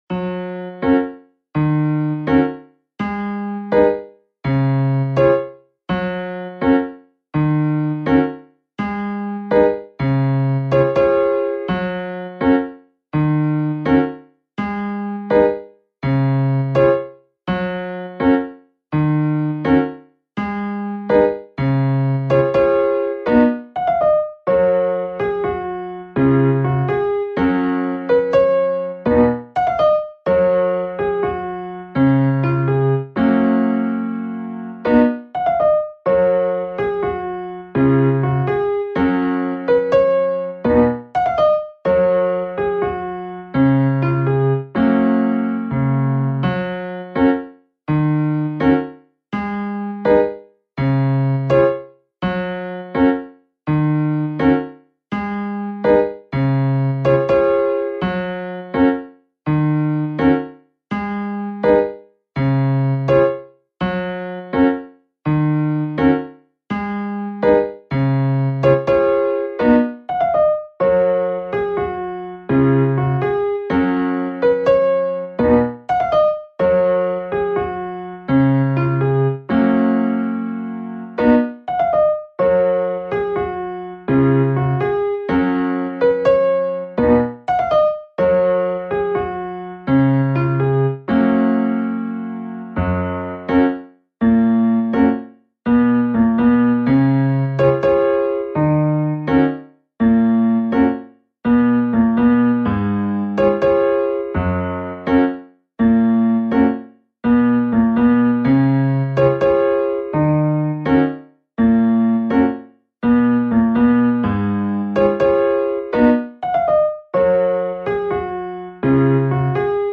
かえるのピアノslowslow.mp3